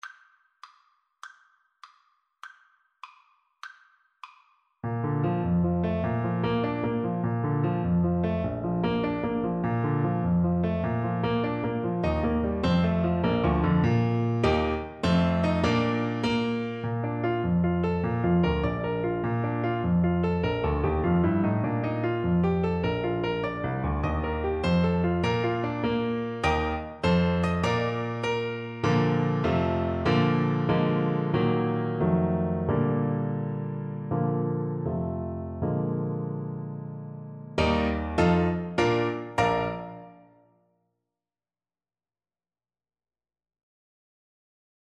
Alto Saxophone
Bb major (Sounding Pitch) G major (Alto Saxophone in Eb) (View more Bb major Music for Saxophone )
Allegretto . = c. 100
6/8 (View more 6/8 Music)
Traditional (View more Traditional Saxophone Music)
pop_goes_ASAX_kar1.mp3